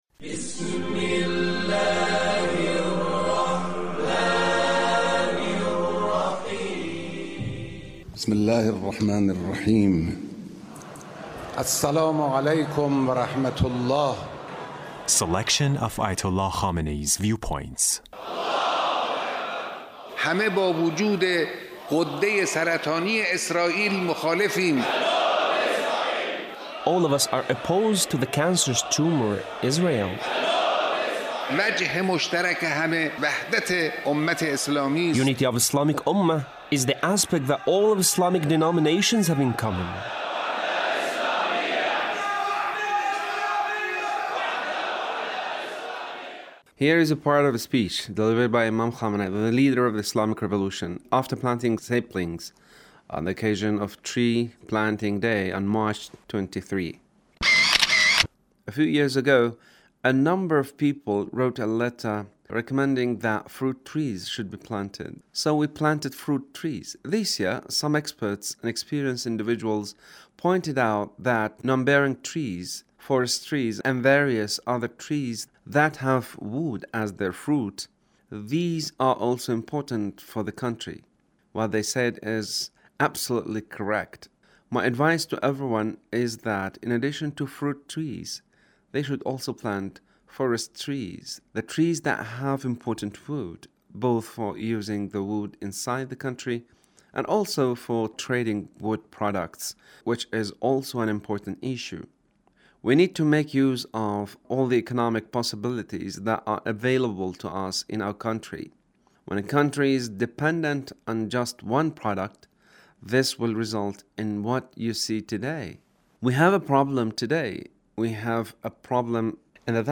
Leader's Speech on Tree Planting Day